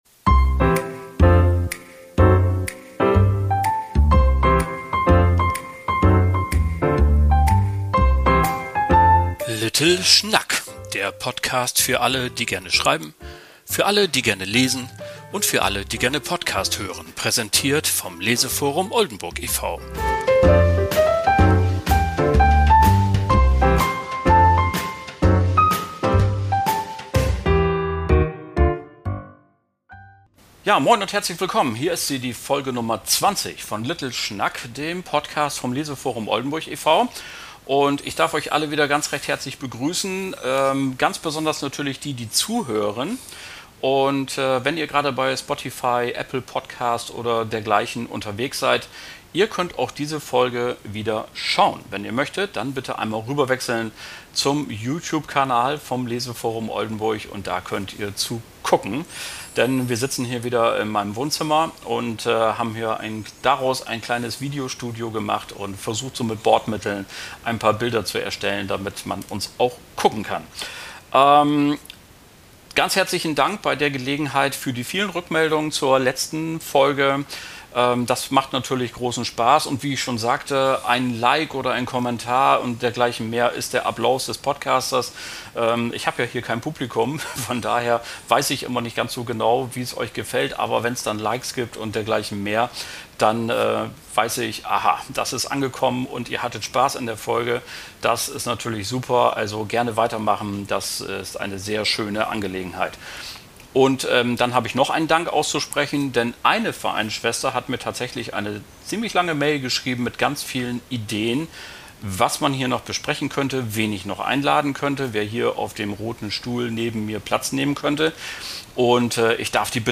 Ein Gespräch über den Siegeszug der Künstlichen Intelligenz und die Auswirkungen auf die Literaturschaffenden.